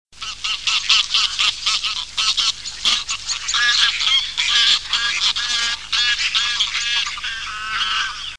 Oie cendrée
Anser anser